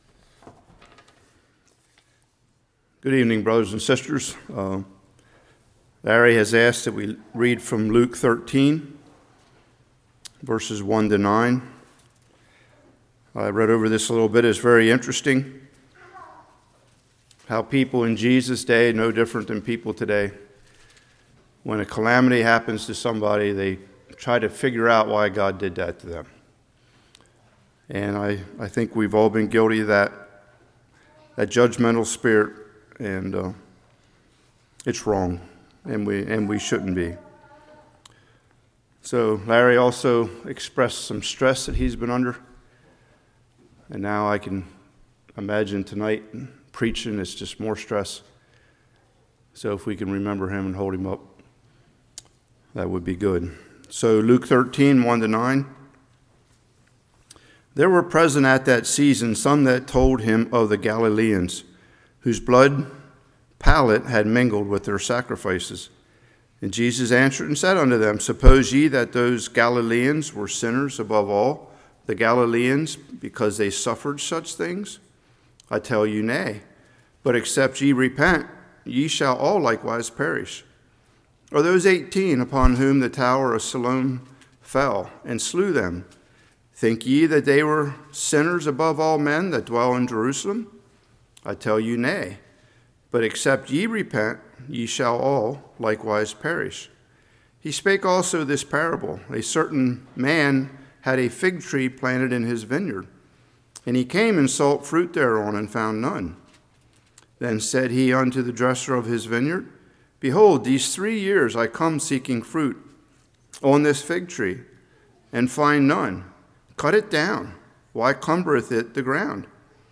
Luke 13:1-9 Service Type: Evening Suffering is call to repentance.